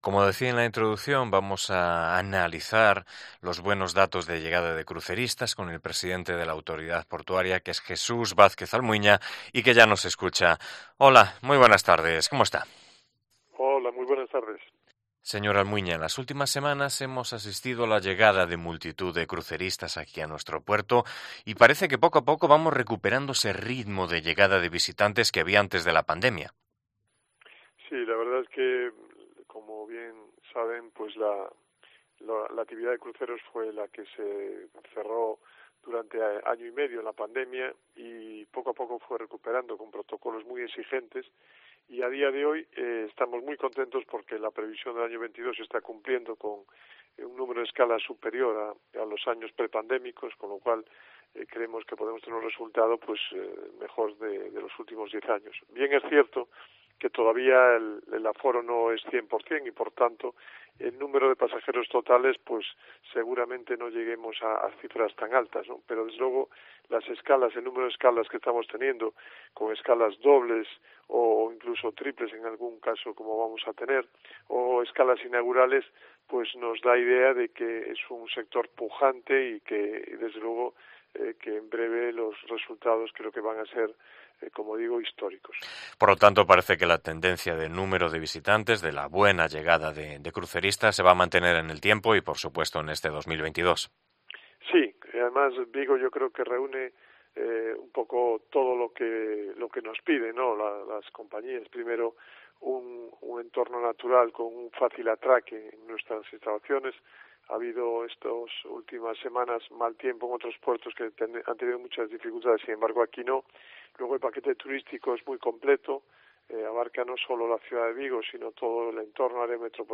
Hablamos con el presidente de la Autoridad Portuaria de Vigo sobre la actualidad de nuestro puerto, por ejemplo, la venta de Barreras